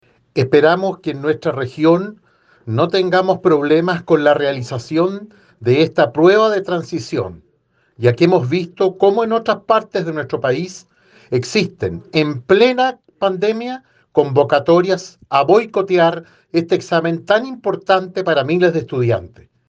El Senador, Iván Moreira indicó que, de materializarse este tipo de protestas en la región de Los Lagos, perjudicarán únicamente a los miles de estudiantes que sueñan con ingresar a la universidad.